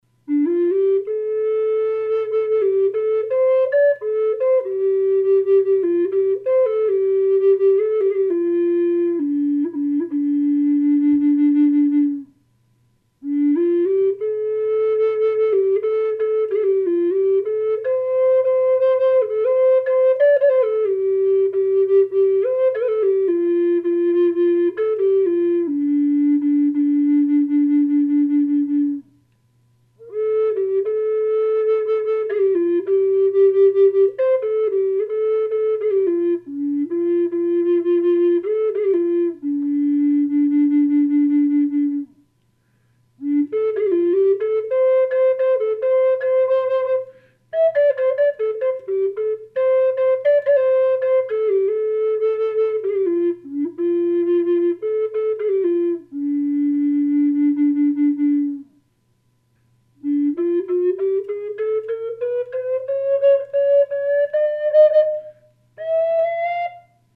Colorado Quacking Aspen Bottle nose Dolphin inlay Flute in the key of low Dm.
Gorgeous voice & tone
Sound sample with no effect
dolphin-aspen-d-minor-1.mp3